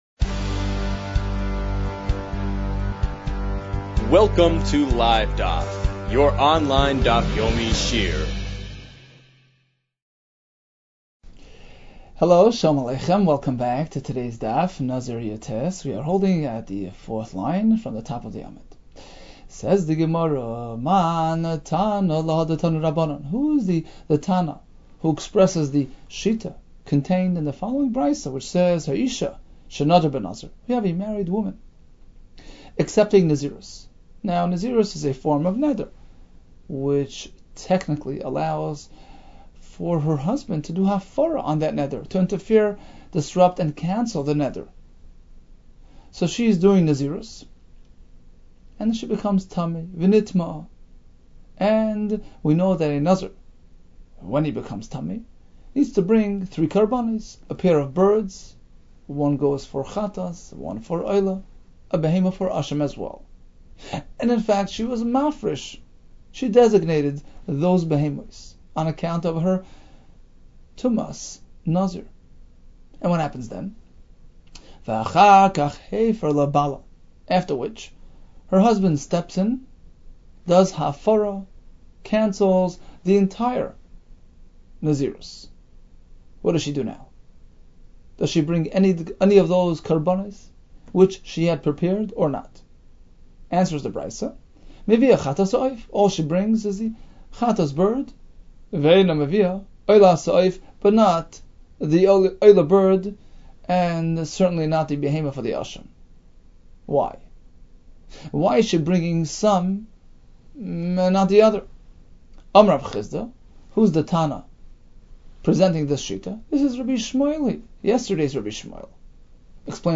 Nazir 18 - נזיר יח | Daf Yomi Online Shiur | Livedaf